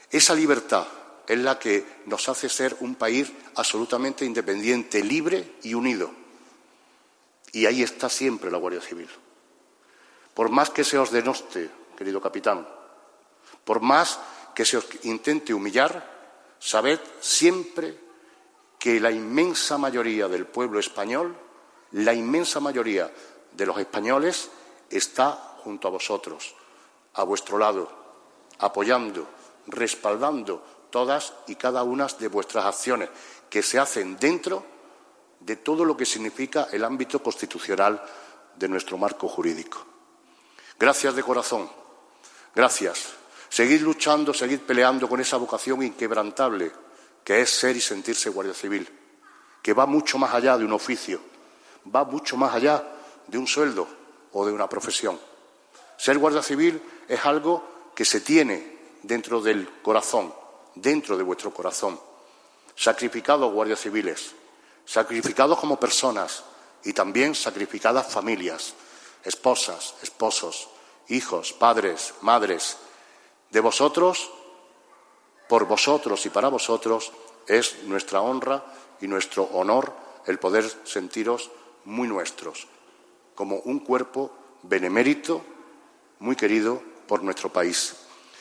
Manolo Barón alabó, durante su intervención, la labor de los agentes y responsables de la Guardia Civil como garantes de una libertad que hace que seamos un país "independiente, libre y unido".
Cortes de voz